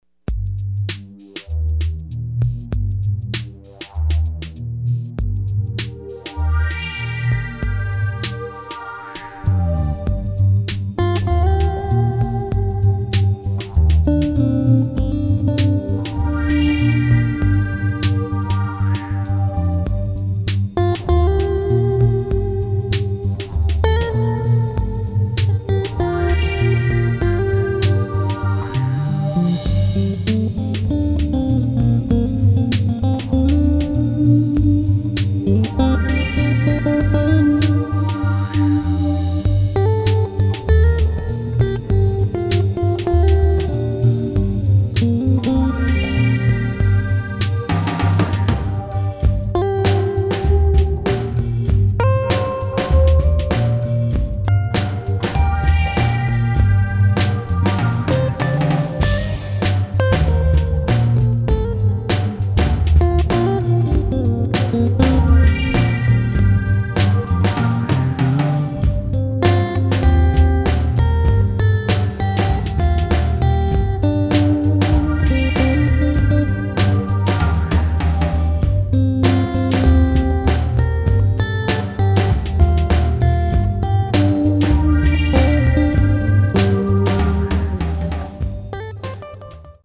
moody atmospheric grooves